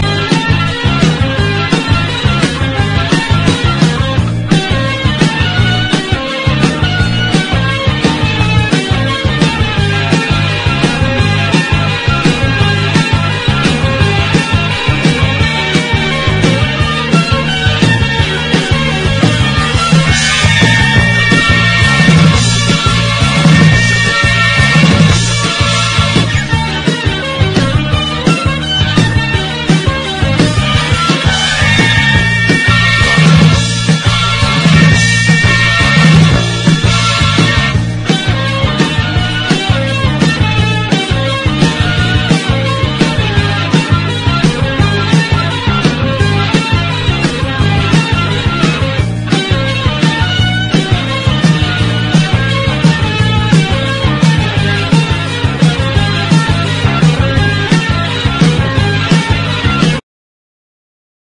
ROCK / 70'S / PROGRESSIVE ROCK / NEW WAVE / AMBIENT
一切ハネないベタベタ・ビートに軽快なカッティング